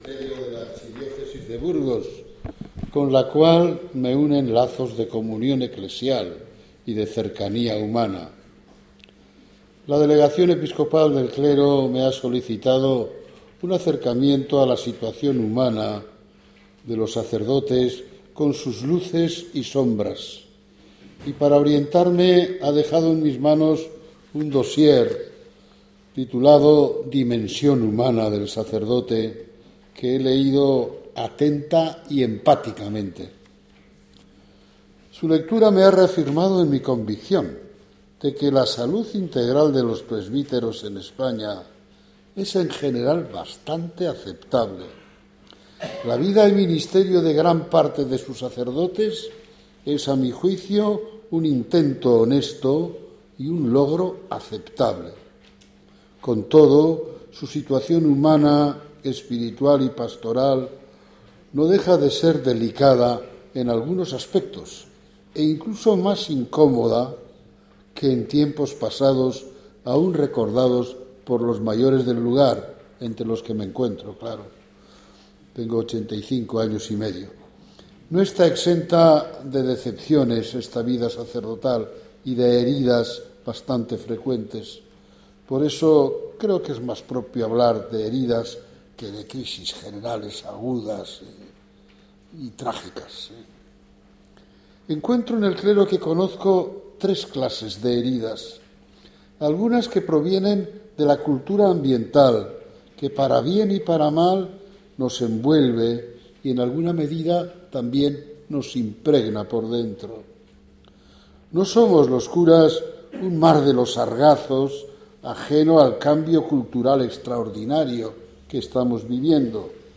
juan-maria-uriarte-charla-1.mp3